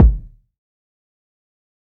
TC Kick 24.wav